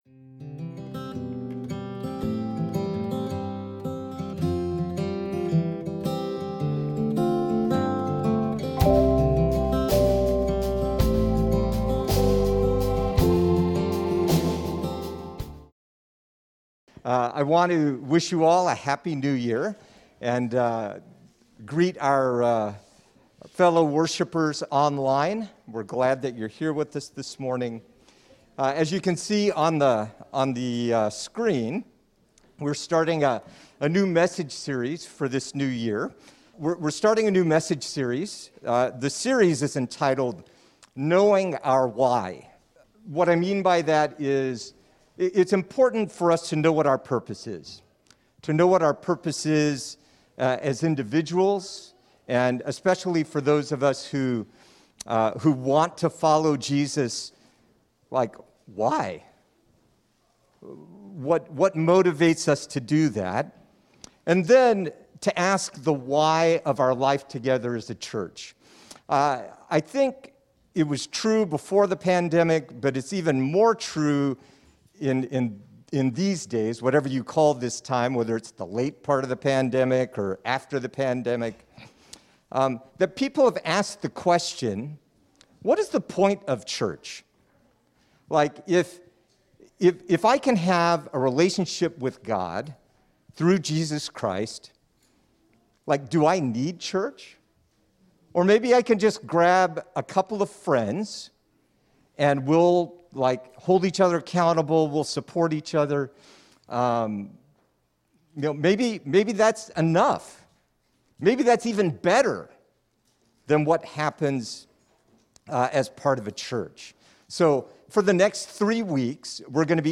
In the first message in our Winter mini-sermon series we ask what happens when individuals or even the church as a whole lose their core identity and purpose. If that happens, what can we do?